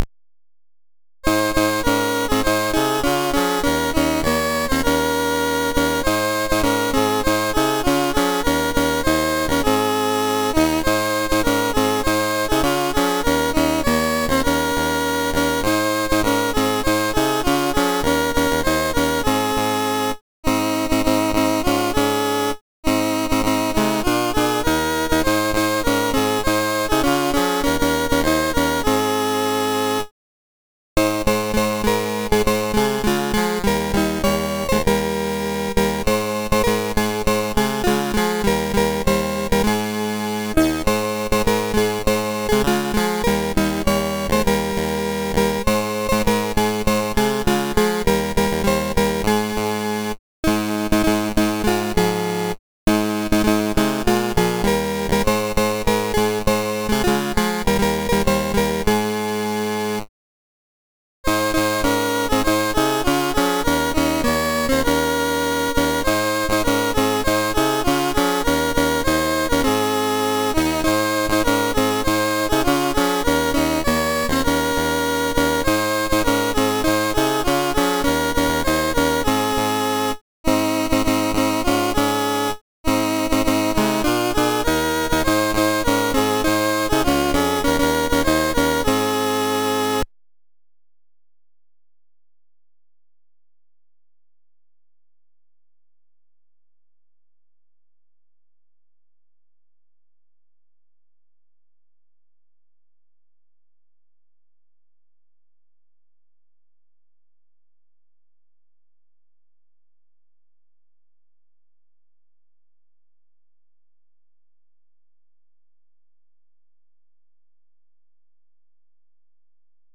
Loadstar 217 home *** CD-ROM | disk | FTP | other *** search / Loadstar 217 / 217.d81 / angelina.mus ( .mp3 ) < prev next > Commodore SID Music File | 2022-08-26 | 2KB | 1 channel | 44,100 sample rate | 3 minutes